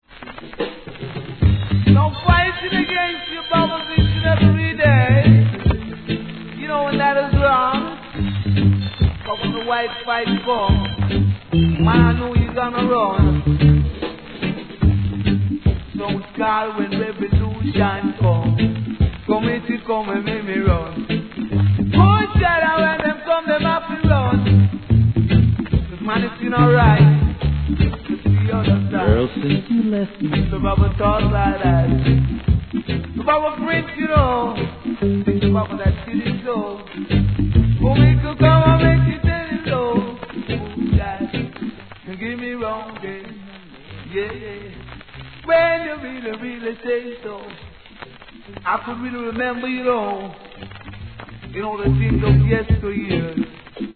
b/wはノイズ大きいです
REGGAE